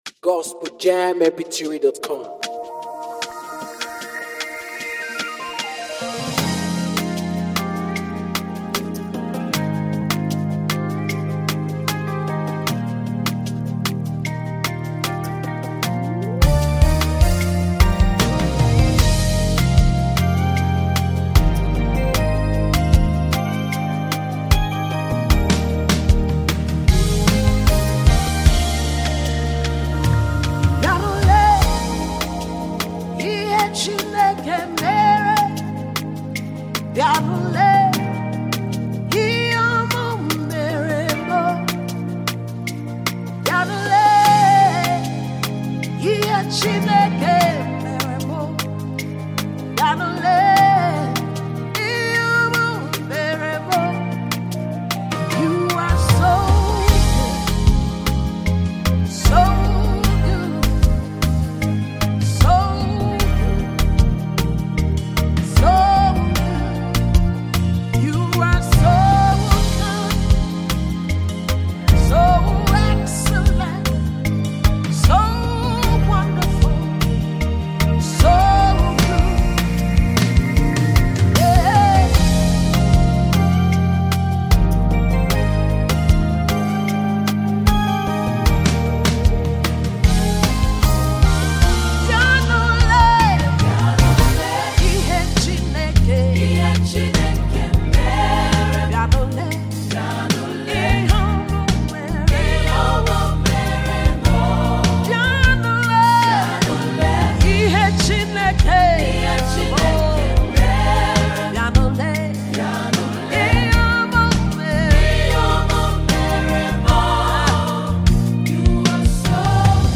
powerful sound of praise